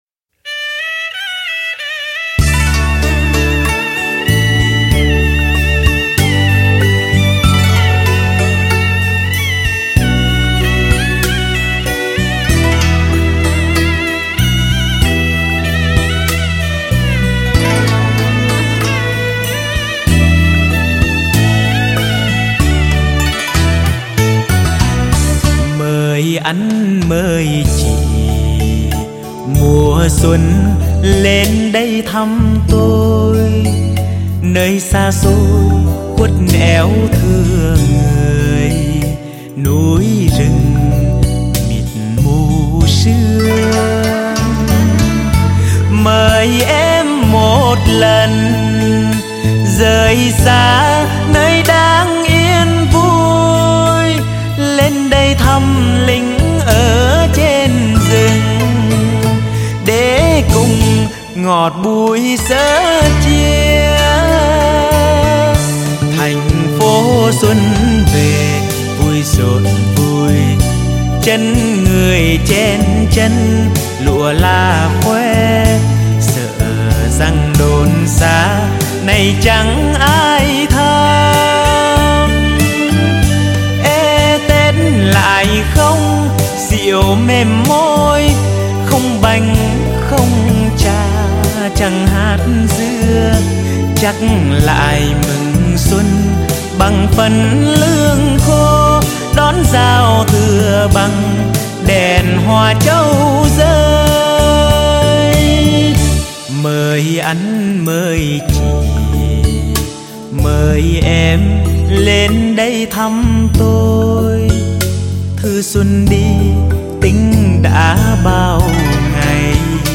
* Thể loại: Xuân